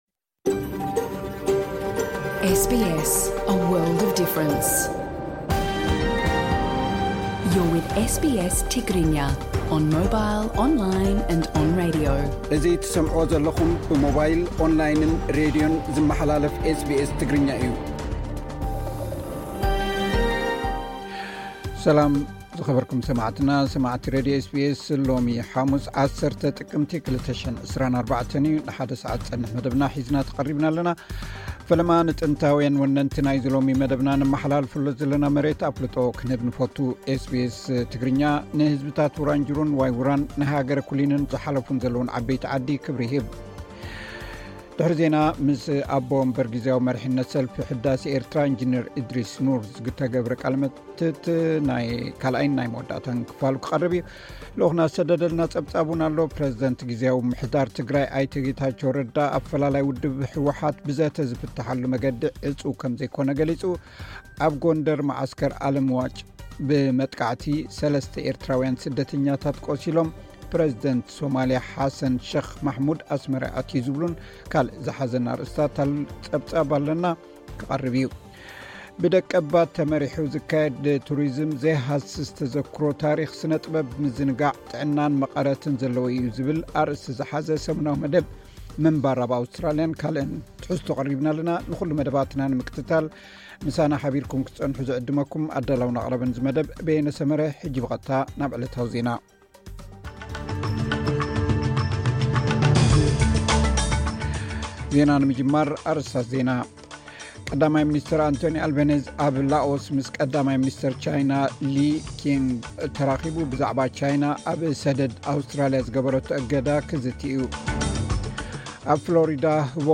ዕለታዊ ዜና ኤስ ቢ ኤስ ትግርኛ (10 ጥቅምቲ 2024)